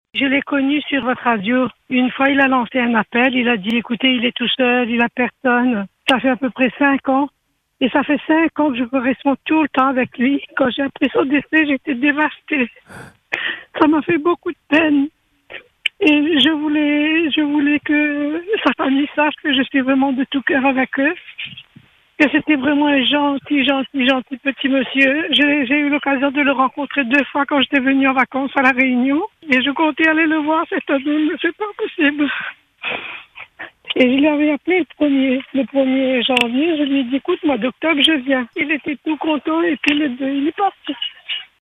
Hier, c’est une voix brisée qui nous a raconté la fin de cette histoire.
Un appel chargé d’émotion, de souvenirs… et de reconnaissance aussi pour ce lien né grâce à l’antenne.